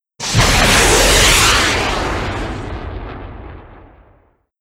missle launch.wav